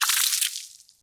Splash.wav